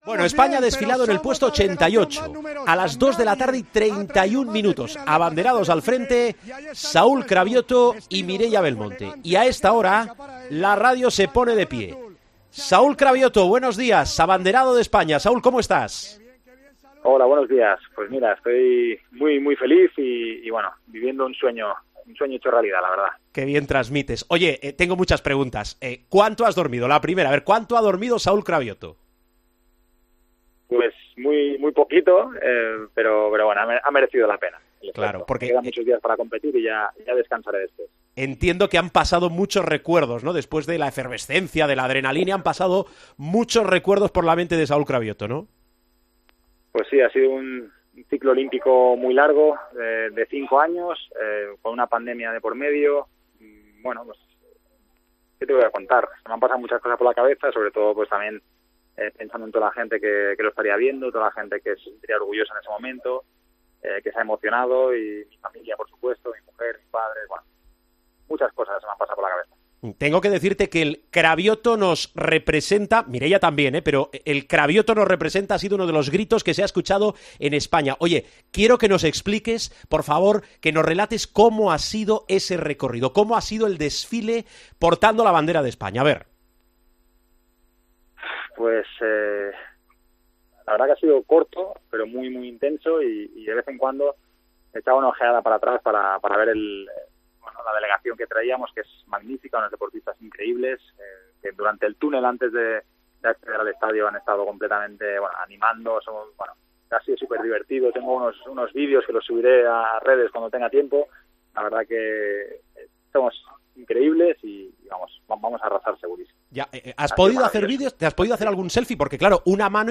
El palista Saúl Craviotto, abanderado de la delegación española en la ceremonia de inauguración de los Juegos Olímpicos de Tokyo 2020 junto a Mireia Belmonte, relató su experiencia en El Partidazo de COPE.